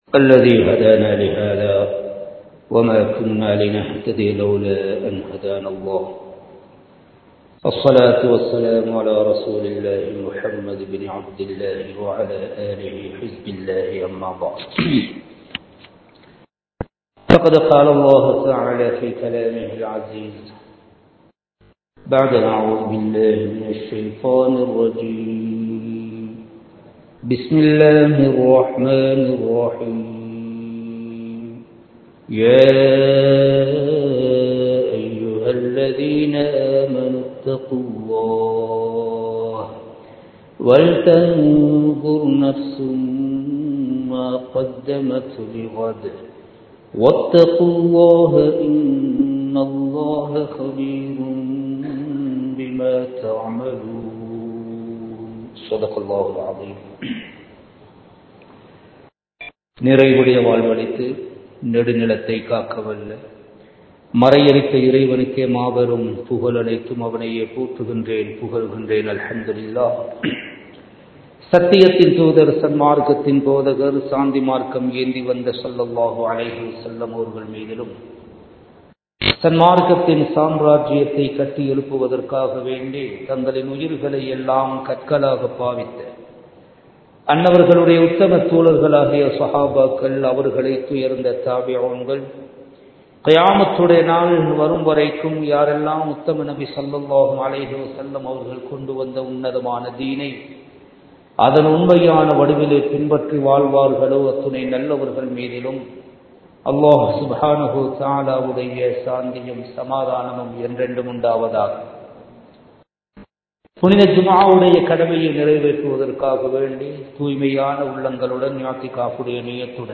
மறுமையை வெல்வோம் | Audio Bayans | All Ceylon Muslim Youth Community | Addalaichenai
Kattukela Jumua Masjith